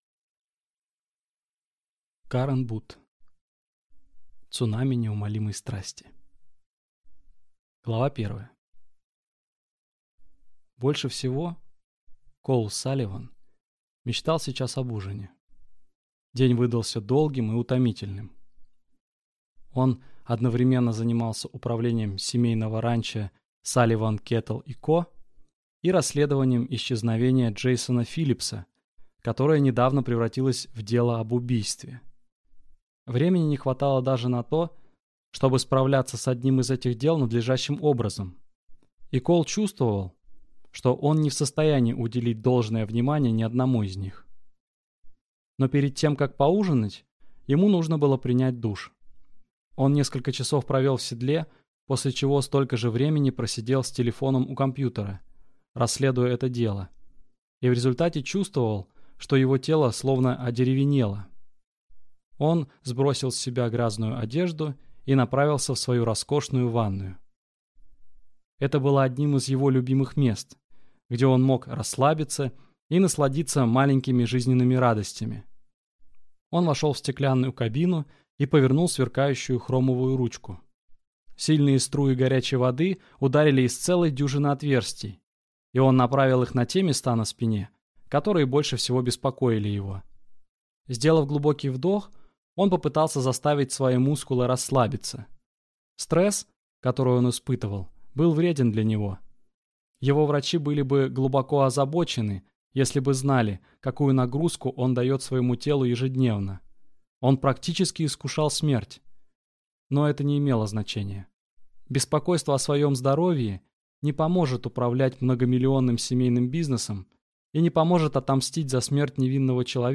Аудиокнига Цунами неумолимой страсти | Библиотека аудиокниг